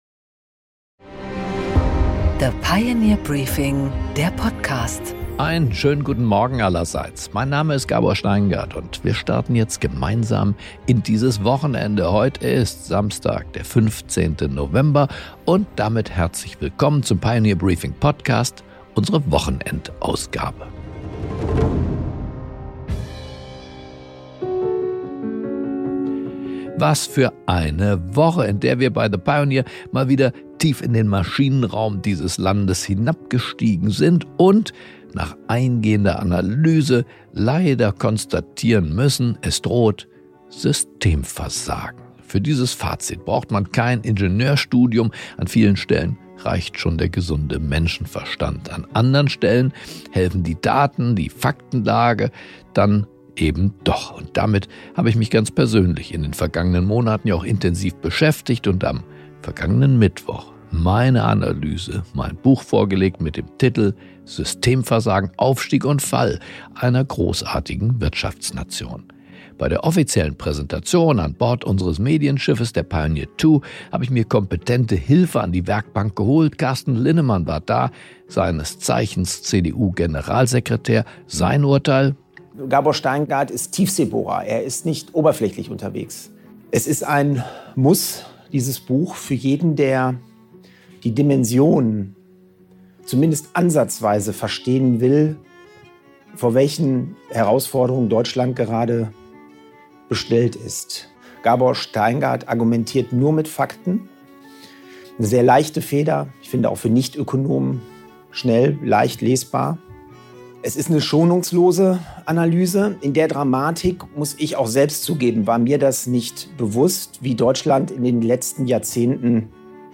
Gabor Steingart präsentiert die Pioneer Briefing Weekend Edition